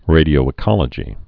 (rādē-ō-ĭ-kŏlə-jē)